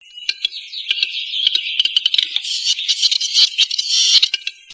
Grand Tétras en parade
Tetrao urugallus
tetras.mp3